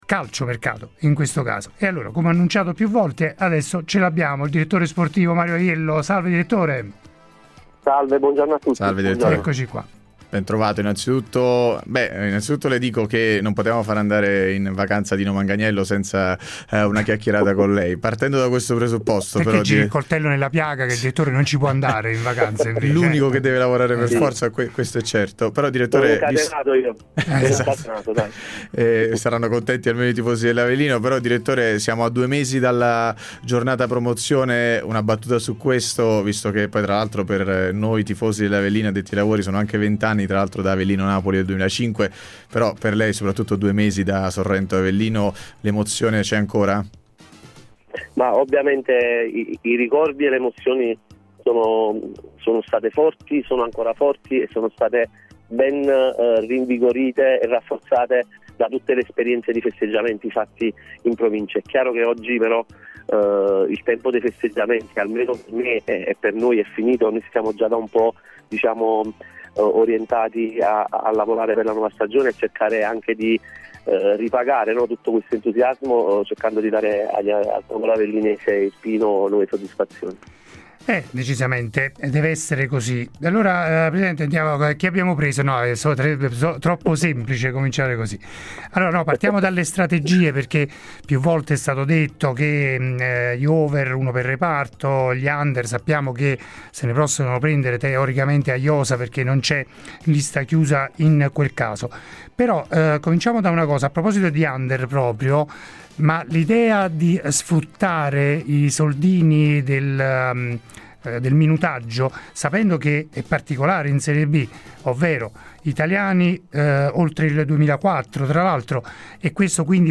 In diretta a Radio Punto Nuovo